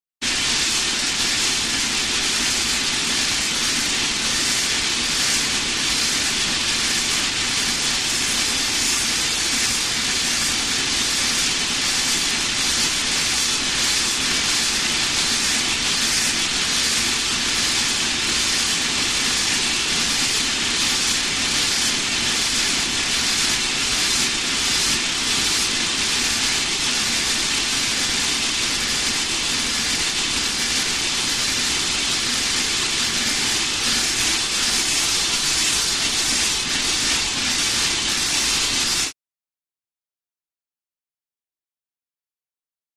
Electrical Sparking; Constant With Slight Rhythmic Pulsing.